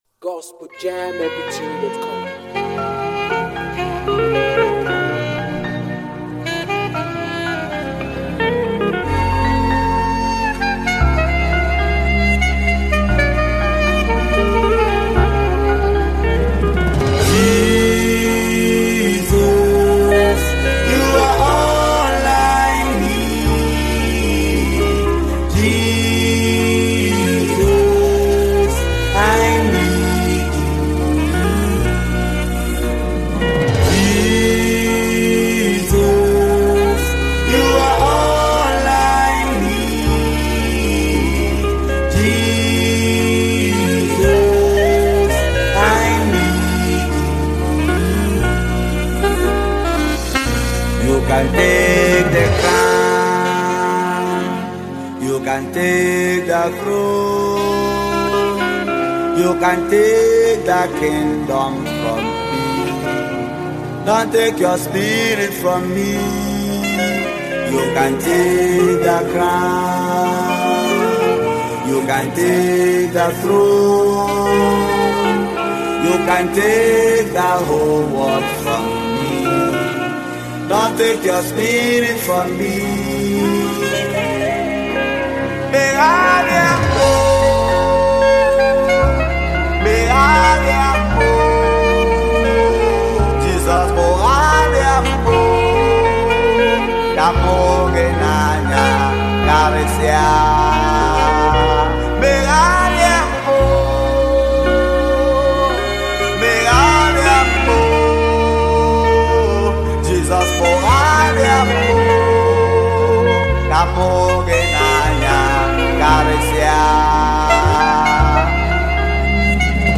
heartfelt gospel song